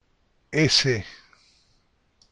Letraseseh